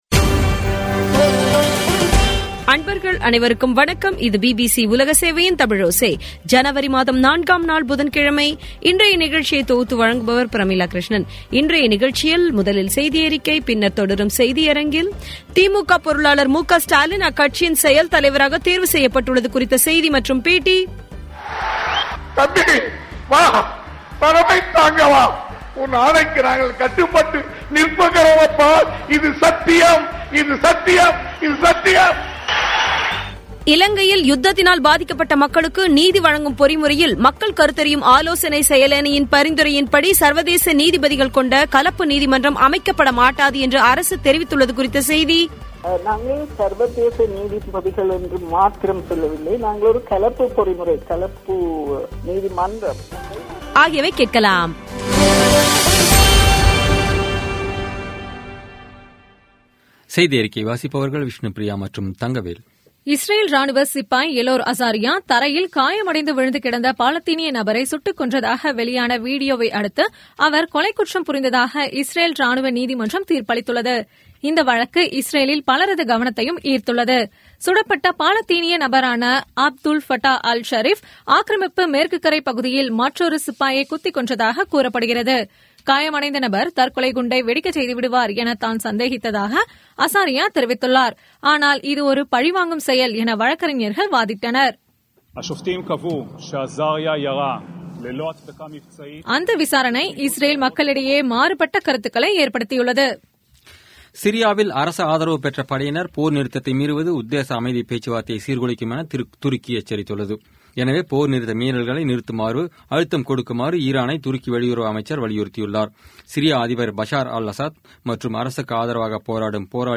இன்றைய நிகழ்ச்சியில் முதலில் செய்தியறிக்கை, பின்னர் தொடரும் செய்தியரங்கில்திமுக பொருளாளர் மு.க. ஸ்டாலின், அக்கட்சின் செயல் தலைவராக தேர்வு செய்யப்பட்டுள்ளது குறித்த செய்தி மற்றும் பேட்டி